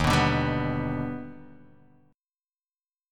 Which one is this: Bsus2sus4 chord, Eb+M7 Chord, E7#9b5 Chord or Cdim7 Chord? Eb+M7 Chord